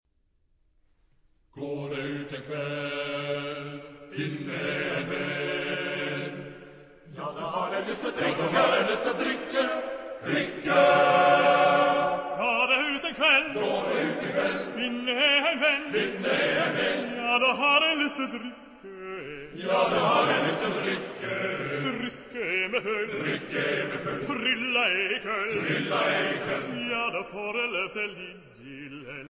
Genre-Style-Form: Romantic ; Secular ; Lied ; ballet
Type of Choir: TTBB  (4 men voices )
Soloist(s): Baryton (1)  (1 soloist(s))
Tonality: G major